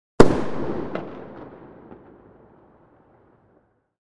Firecracker-explosion-with-echo-sound-effect.mp3